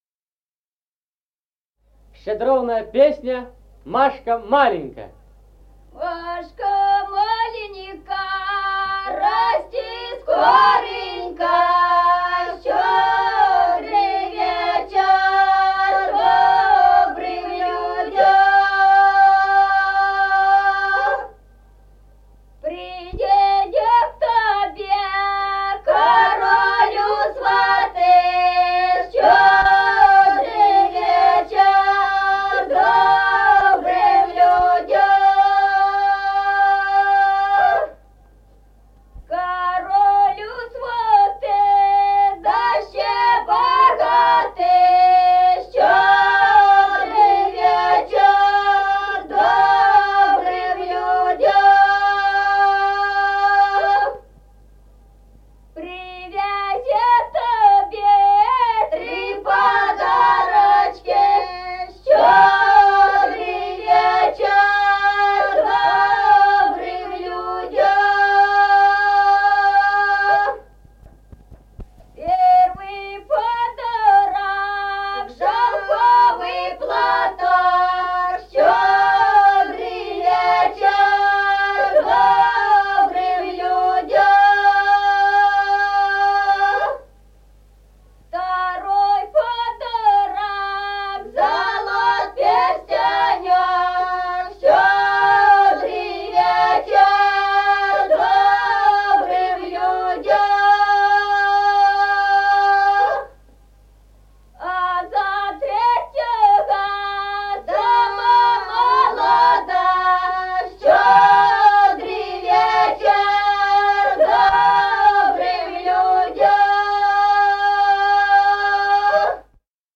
Музыкальный фольклор села Мишковка «Машка маленька», щедровная.